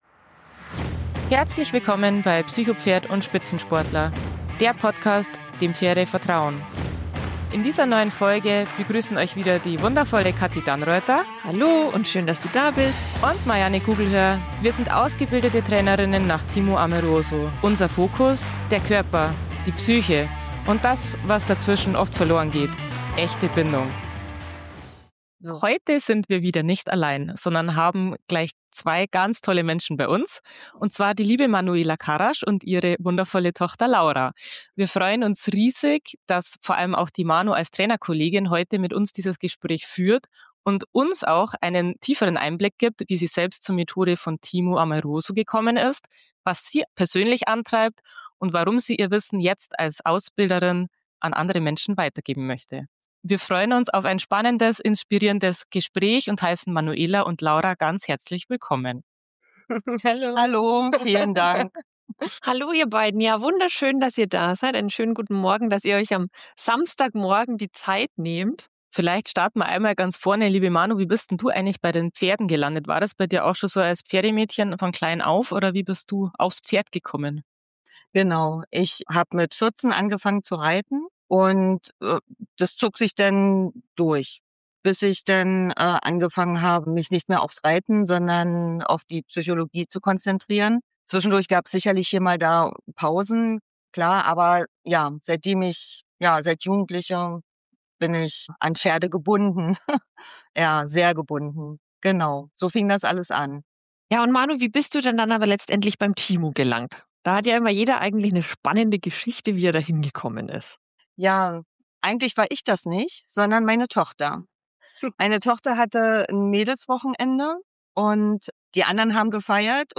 Coco steht stellvertretend für viele Pferde, die gelernt haben zu kämpfen oder still zu werden. In dieser Folge geht es darum, was Pferde uns zeigen, wenn wir bereit sind zuzuhören - und warum Heilung nicht durch Training entsteht, sondern durch Beziehung, Geduld und Verantwortung. Freut euch auf ein ehrliches, berührendes und kritisches Gespräch, das zum Umdenken einlädt – für einen verantwortungsvolleren und achtsameren Umgang mit Pferden.